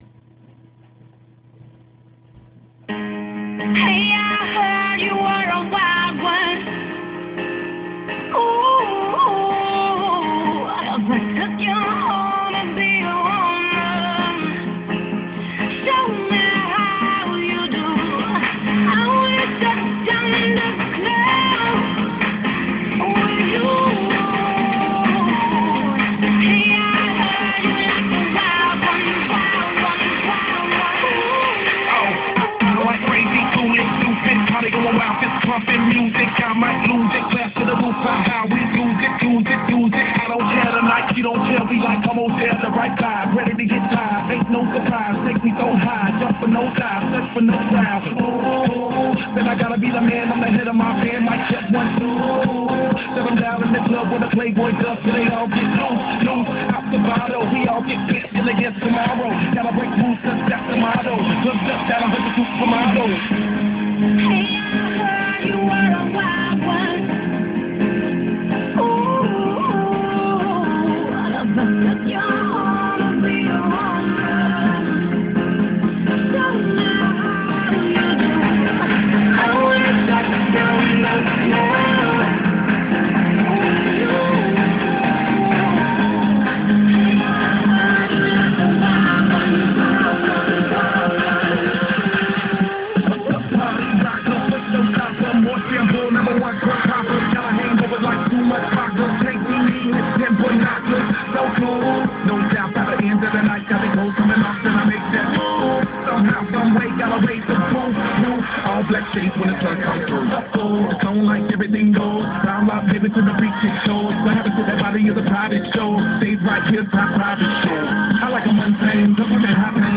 Ниже сама запись ( на диктофон ) , с меня благодарность *